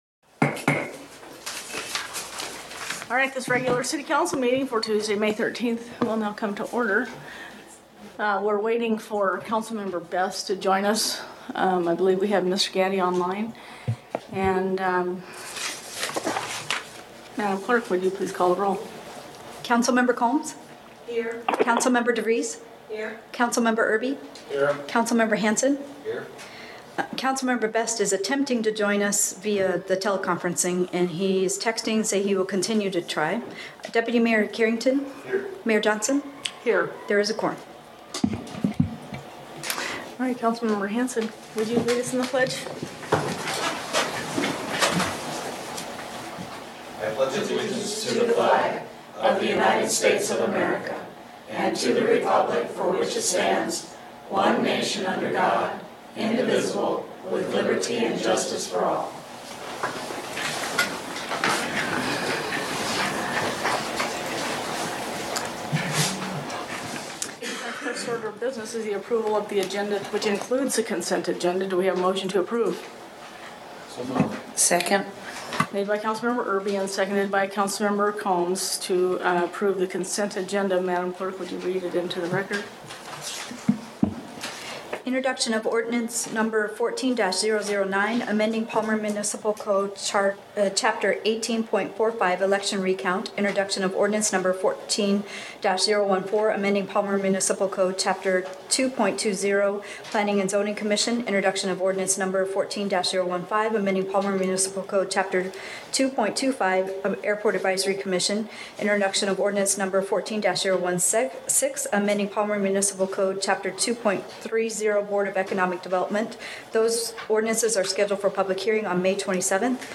Palmer City Council Meeting 5.13.2014